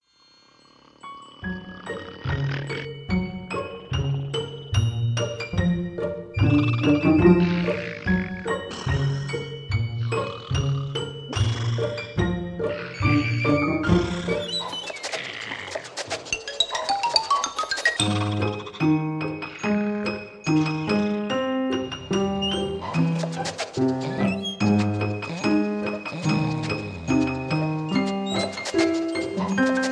Aus dem Kindertanztheater